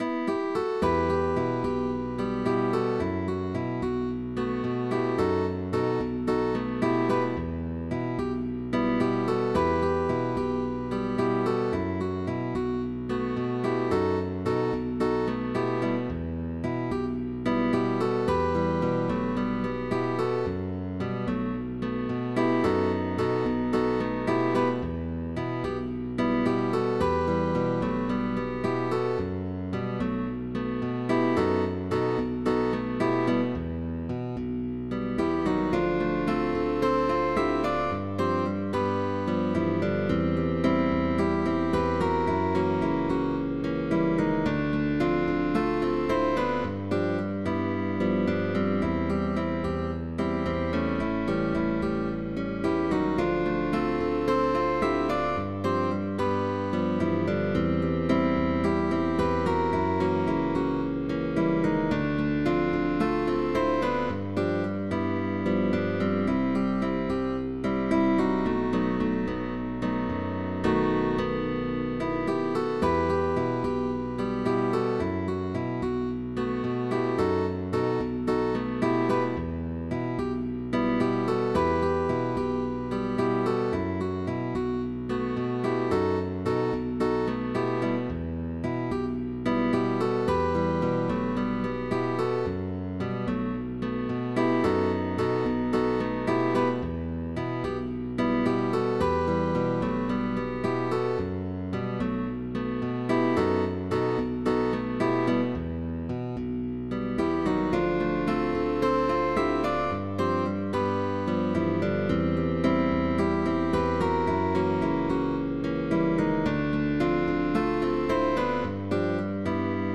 Boogie with a marked Latin character
GUITAR QUARTET // GUITAR ORCHESTRA